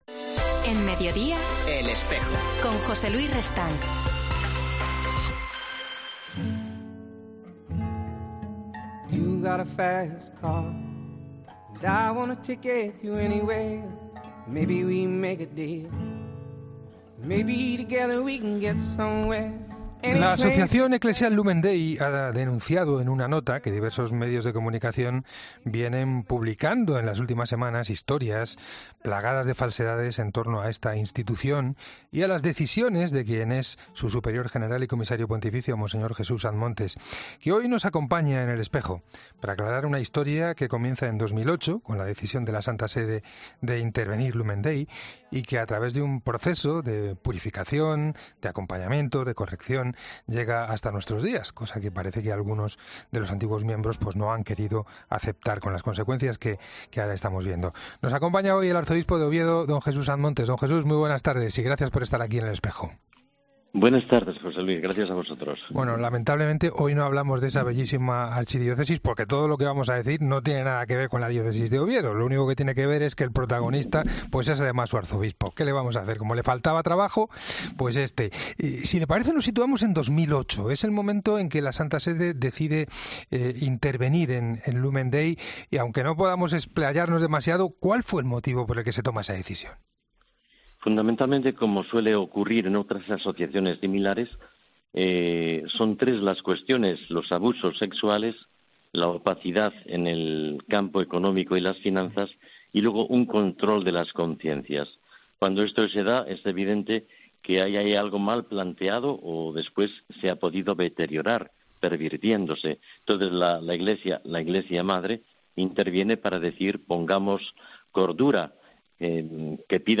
El Arzobispo de Oviedo y Comisario Pontificio y Superior General de Lumen Dei , Mons. Jesús Sanz Montes, ha pasado por los micrófonos de ‘El Espejo’ para aclarar las falsas informaciones que se han vertido desde diversos medios de comunicación sobre esta institución eclesiástica, que fue intervenida por la Santa Sede en el año 2008, ante las malas prácticas que estaban ejerciendo sus gestores.